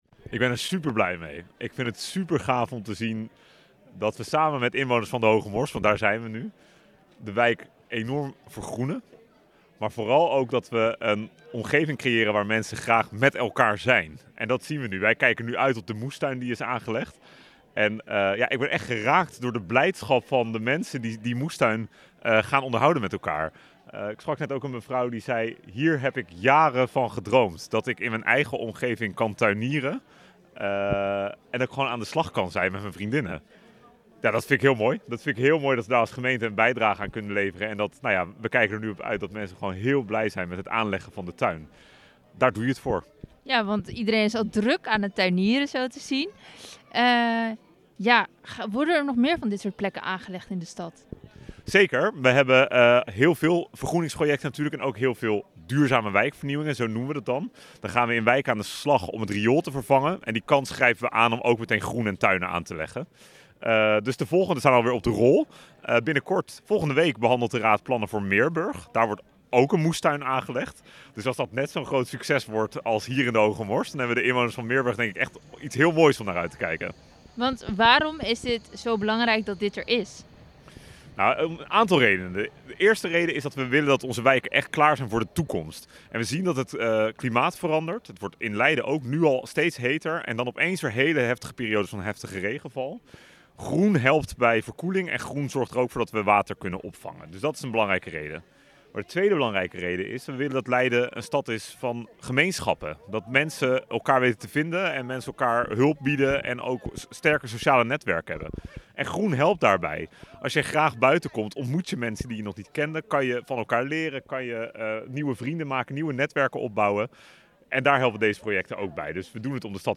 Wethouder Ashley North over de moestuin in de Hoge Mors.